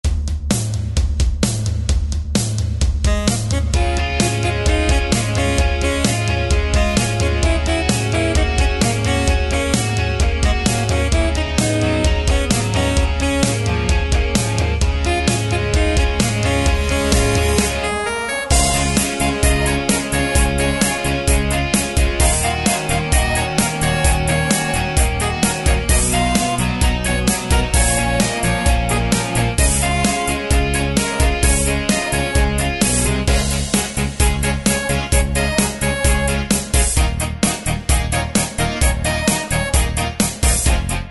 Tempo: 130 BPM.
MP3 with melody DEMO 30s (0.5 MB)zdarma